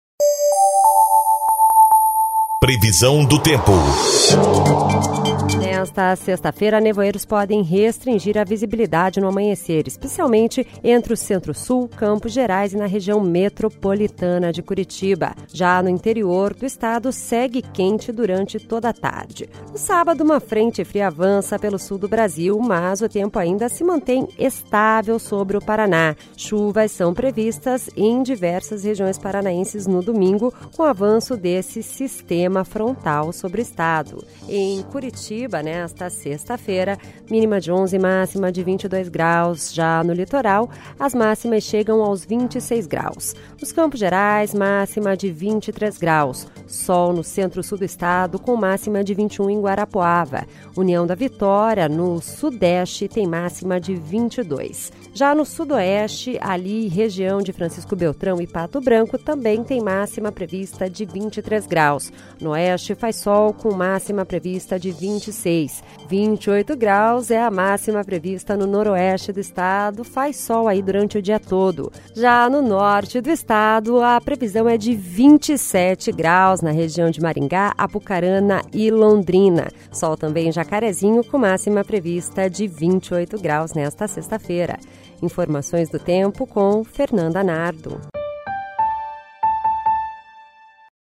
Previsão do Tempo (09/06)